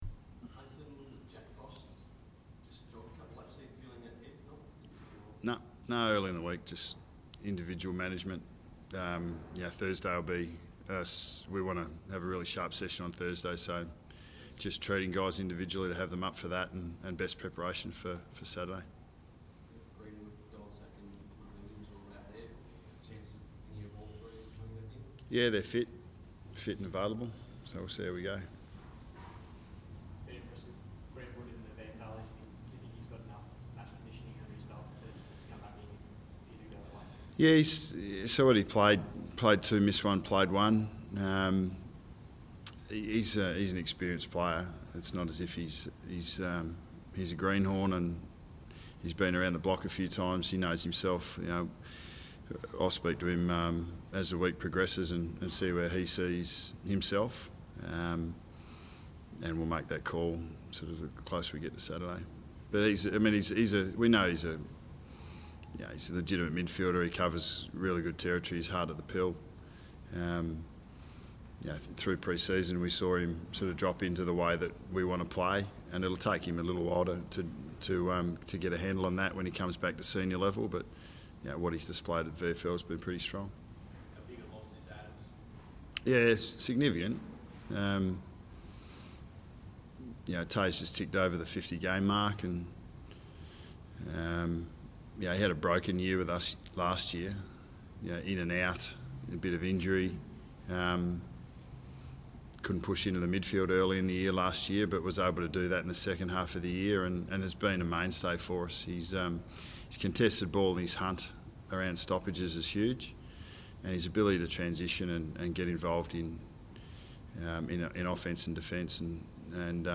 Hear from Collingwood coach Nathan Buckley during his mid-week press conference on Tuesday 14 July 2015.